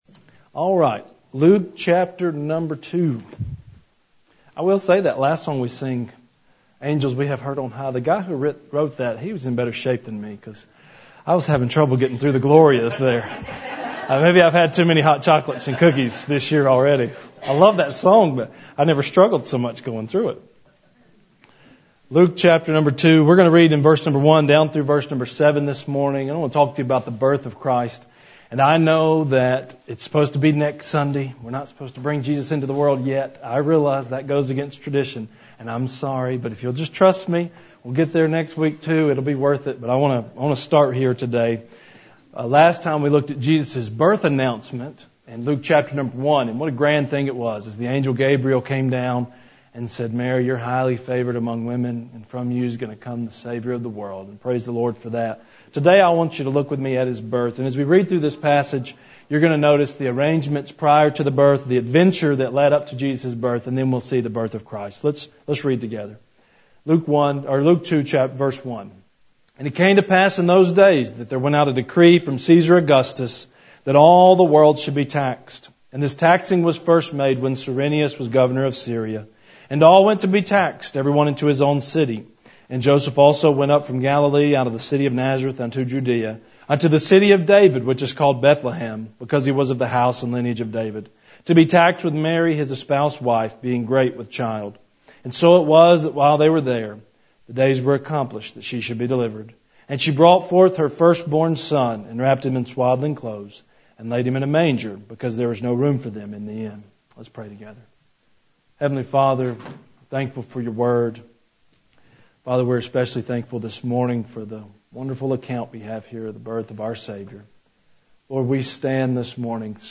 Sermons | Harpeth Baptist Church